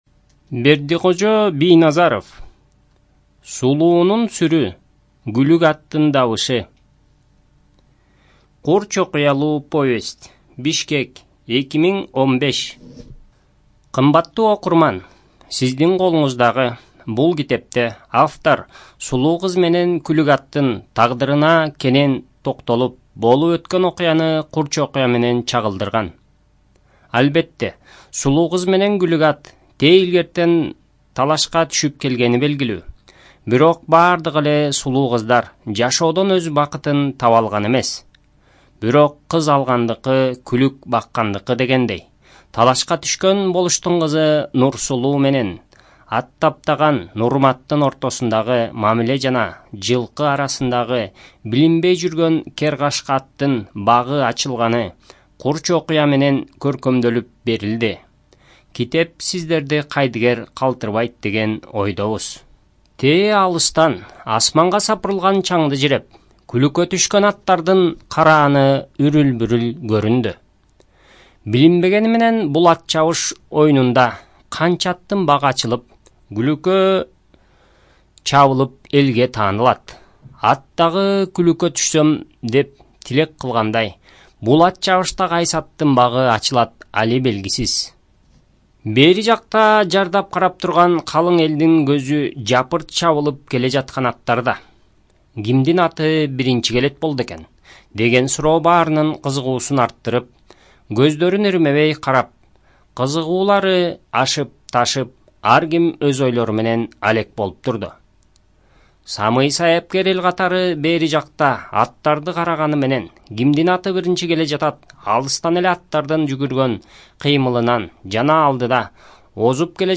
Студия звукозаписиКыргызская Республиканская специализированная библиотека для слепых и глухих